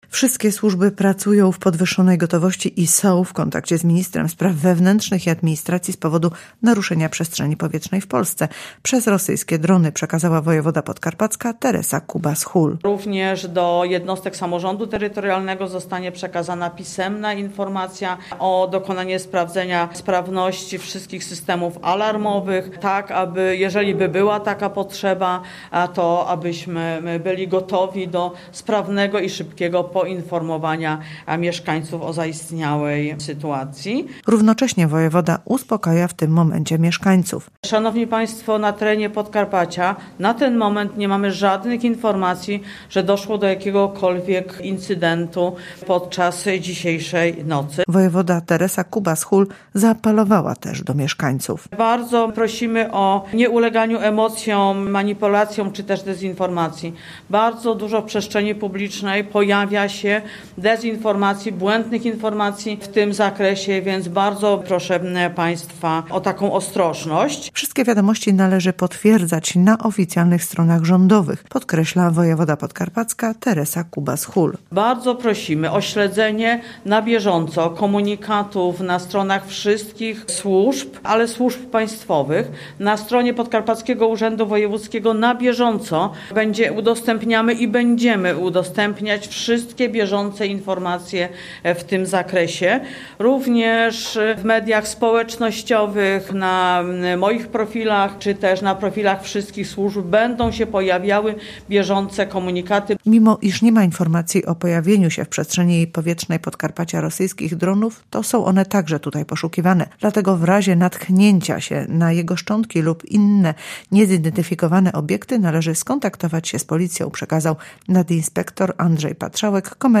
– poinformowała Teresa Kubas-Hul, wojewoda podkarpacki.
konferencja-wojewody.mp3